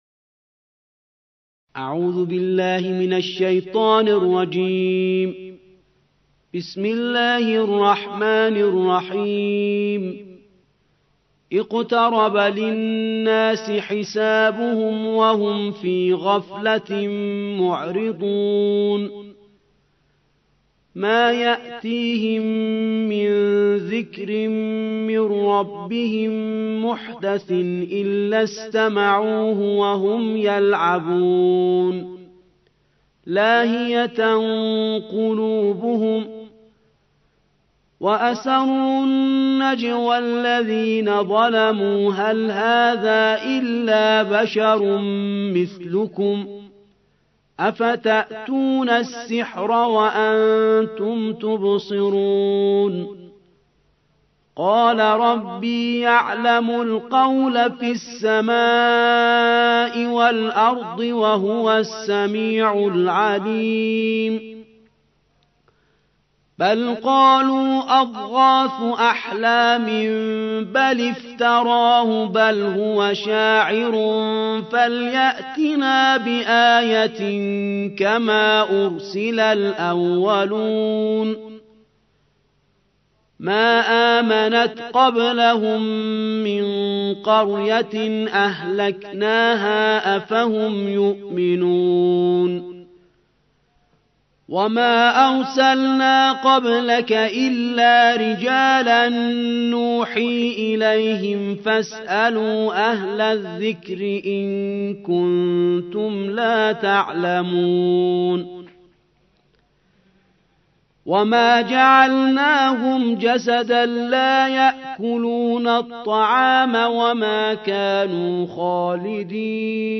الجزء السابع عشر / القارئ